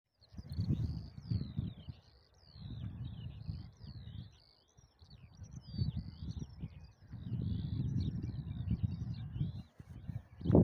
Besonders freute ich mich auf dem Tempelhofer Feld auf die Feldlerchen und ihre Gesänge.
Feldlerche auf dem Tempelhofer Feld
Feldlerche.mp3